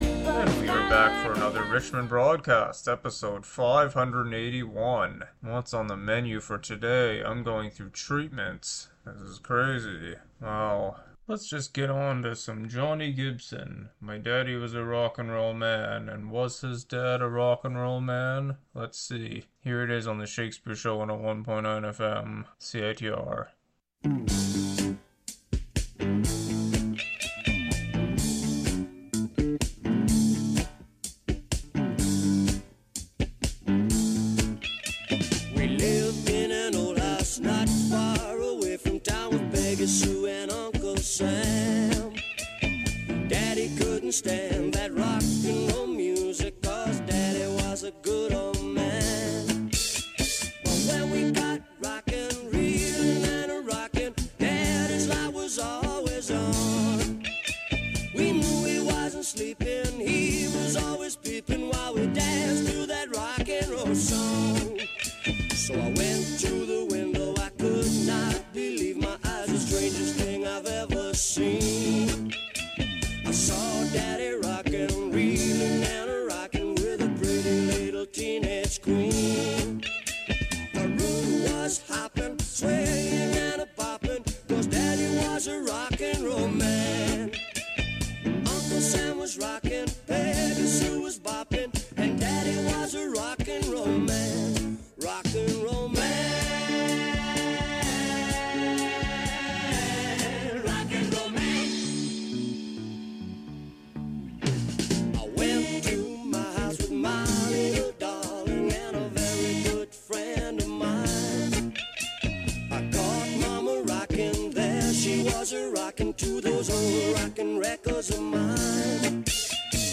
an eclectic mix of music